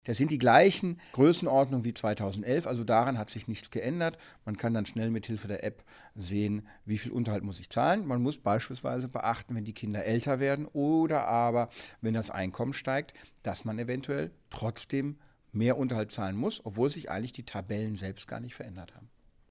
O-Töne / Radiobeiträge, , , , , , ,